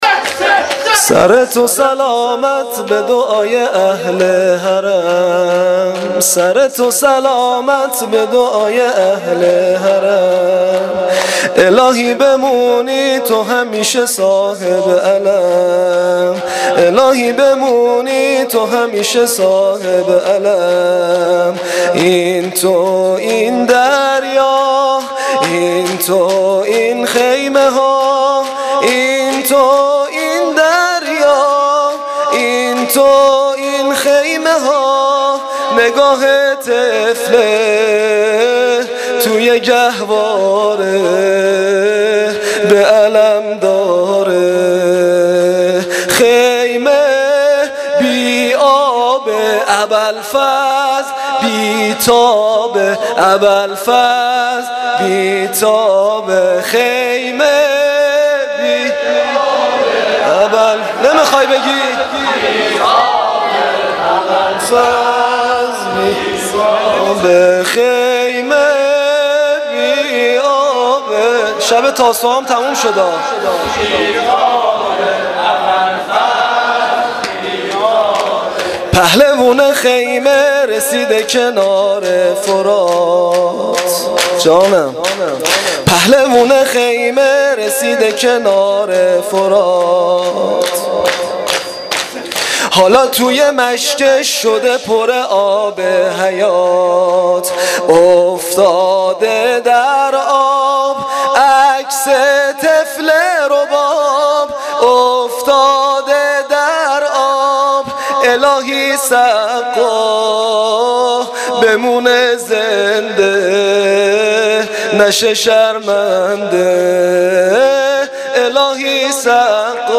شور شب نهم محرم